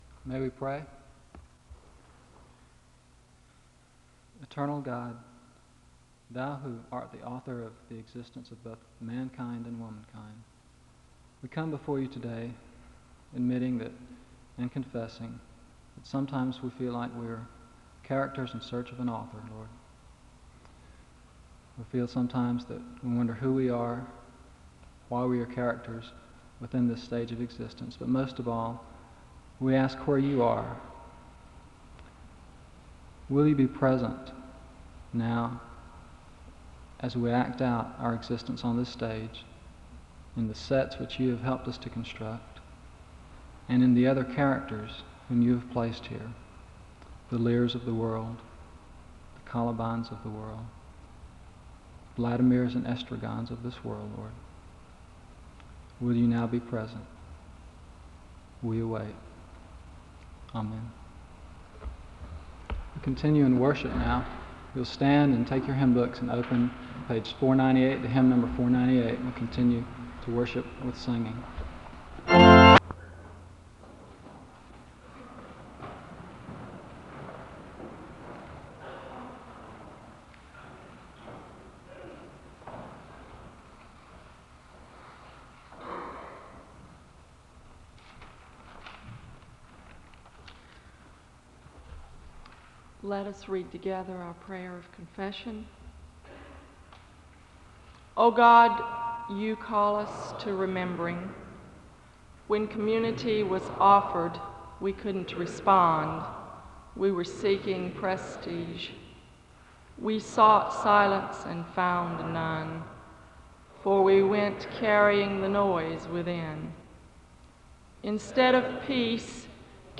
The service opens with a word of prayer (00:00-01:01). The first speaker reads a prayer of confession, and the second speaker reads from Isaiah 4:2-6 and John 14:15-31 (01:02-06:19). The choir leads in a song of worship (06:20-08:47).